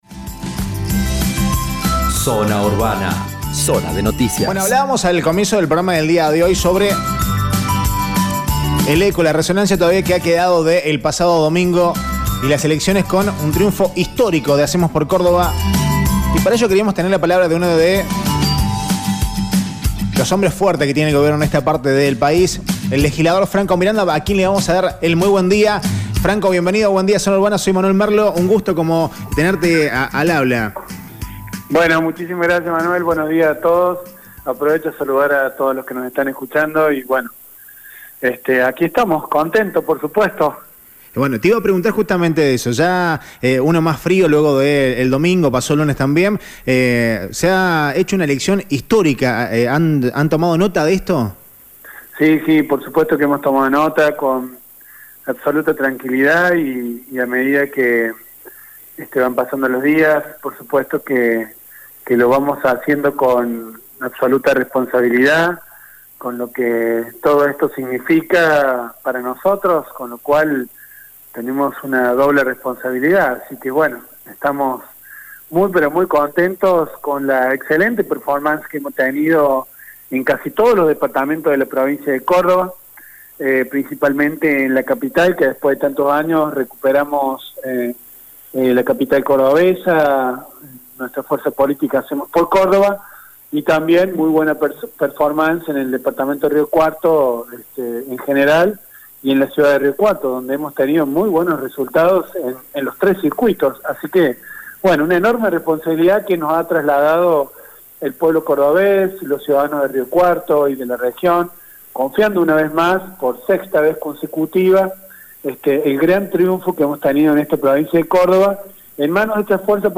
Entrevistas diarias